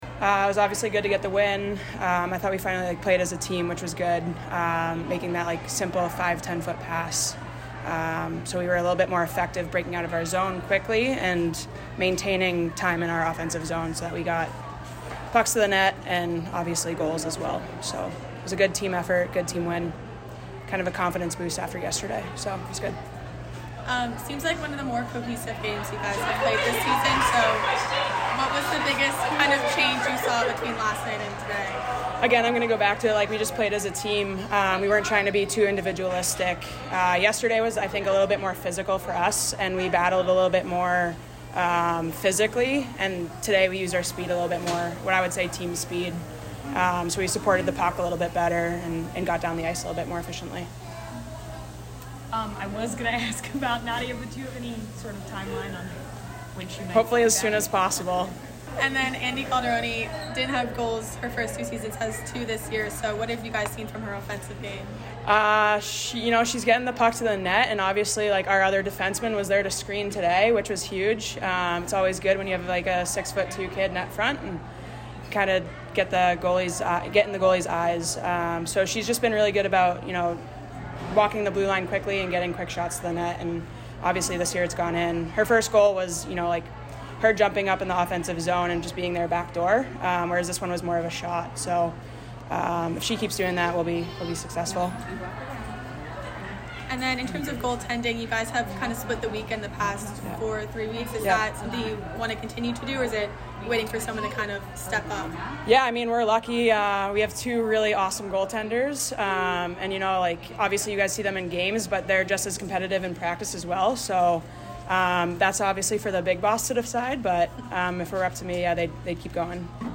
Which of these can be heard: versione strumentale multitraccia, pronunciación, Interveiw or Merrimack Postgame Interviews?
Merrimack Postgame Interviews